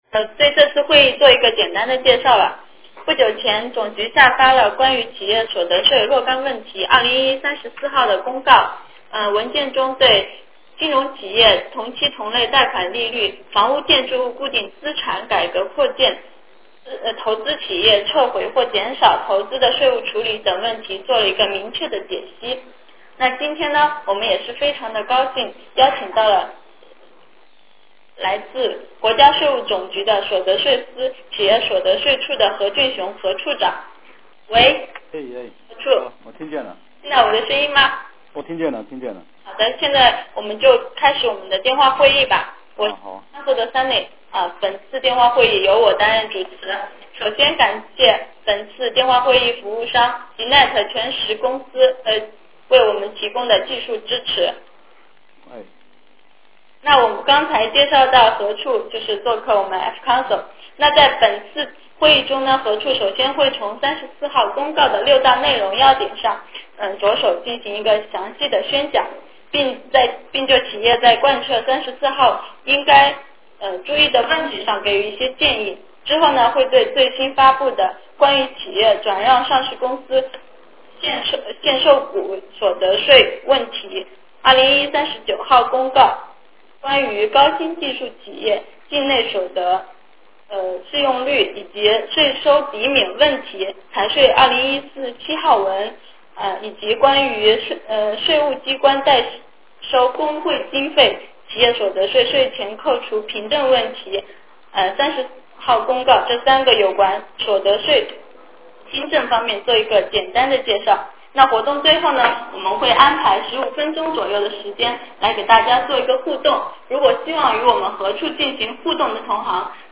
电话会议
Q&A 提问与交流